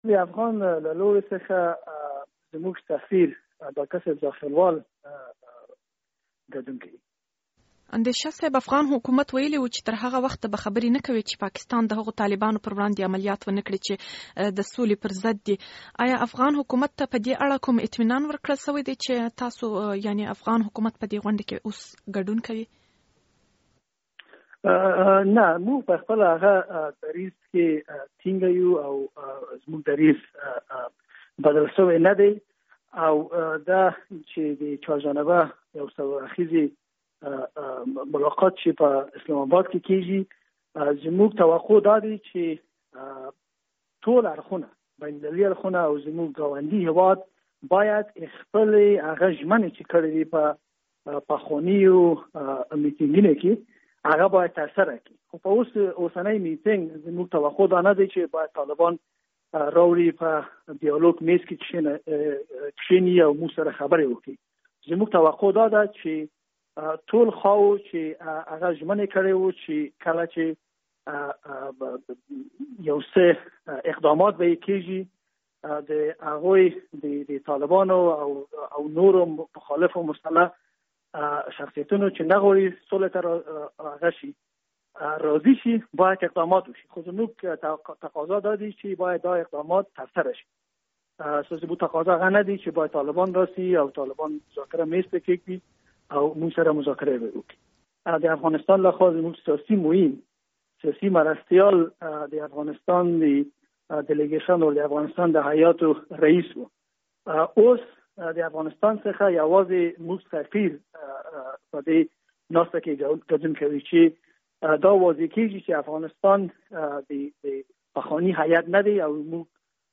له نصیر اندېشه سره مرکه